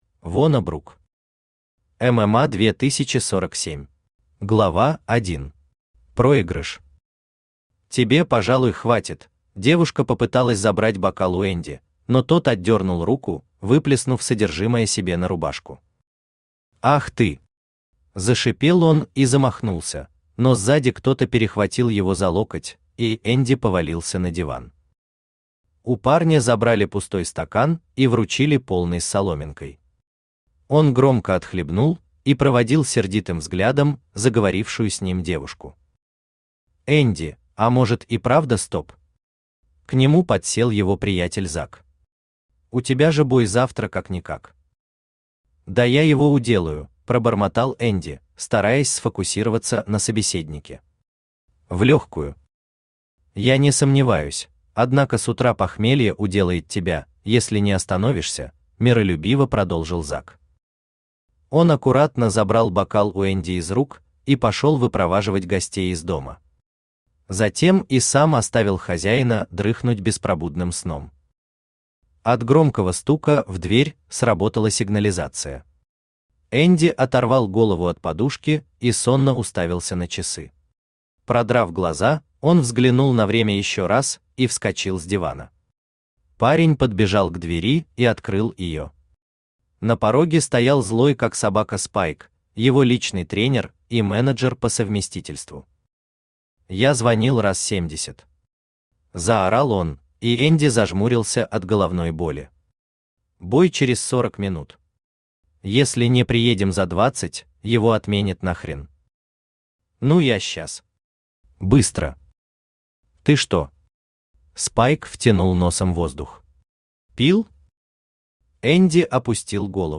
Аудиокнига ММА 2047 | Библиотека аудиокниг
Aудиокнига ММА 2047 Автор Вона Брук Читает аудиокнигу Авточтец ЛитРес.